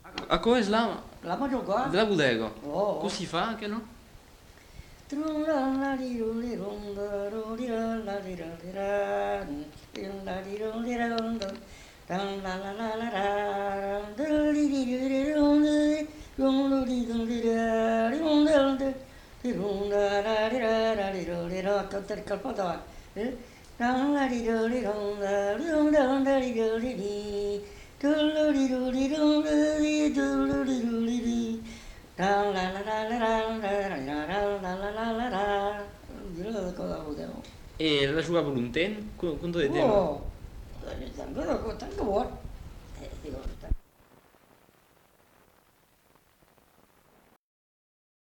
Genre : chant
Effectif : 1
Type de voix : voix d'homme
Production du son : effet de voix
Danse : mazurka
Ecouter-voir : archives sonores en ligne